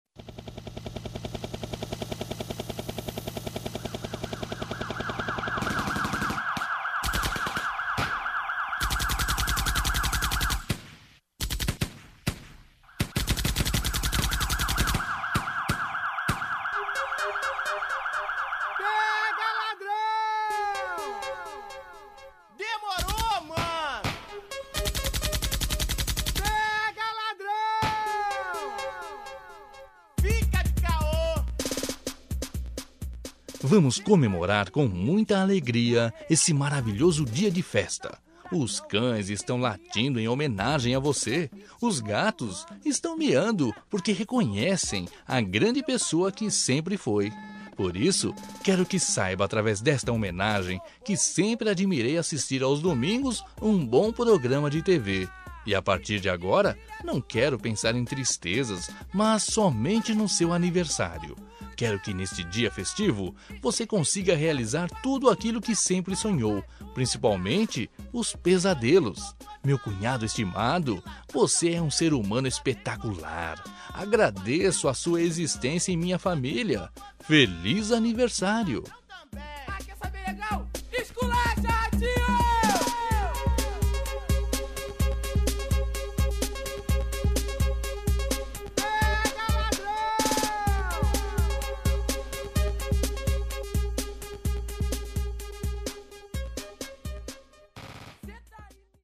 Aniversário de Humor – Voz Masculina- Cód: 200208